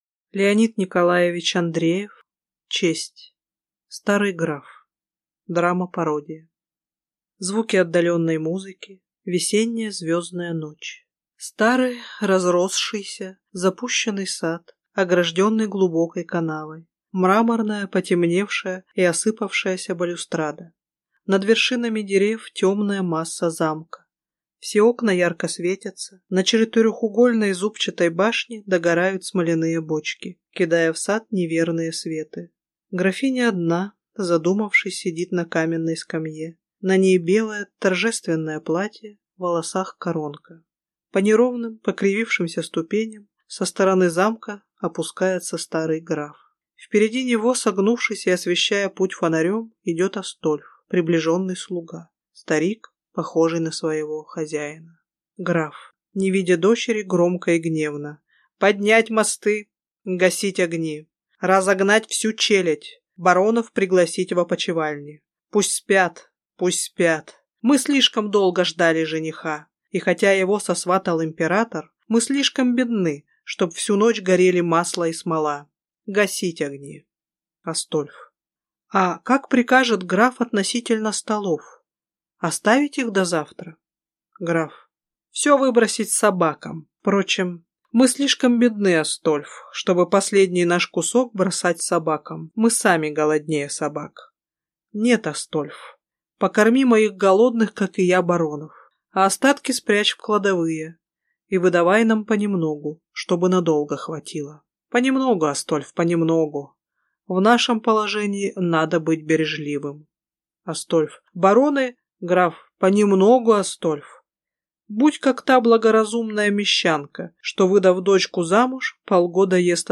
Aудиокнига Честь Автор Леонид Андреев Читает аудиокнигу